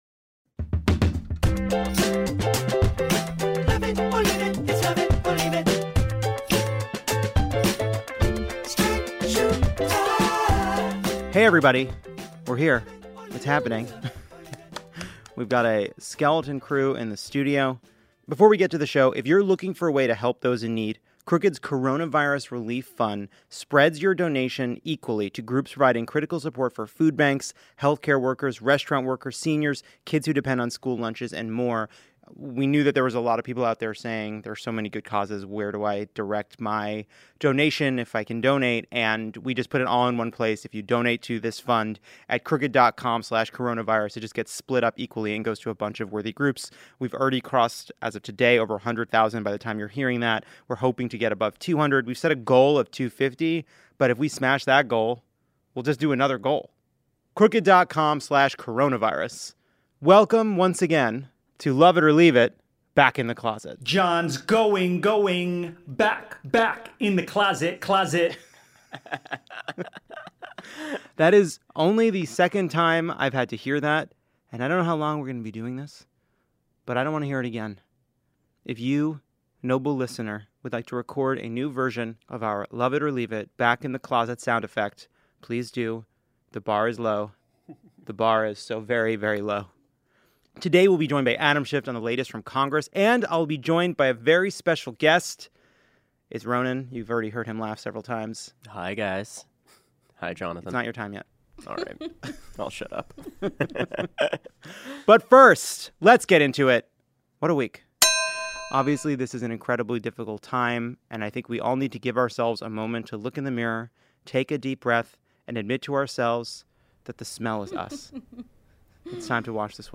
Ronan Farrow stops by to take calls from couples working from home. And Adam Schiff calls in to give us an update on the congressional response to the virus and his struggles with veganism during impeachment. Plus, listeners call in with their own personal high notes and Ronan tells me why he doesn't consider Cheetos a staple food.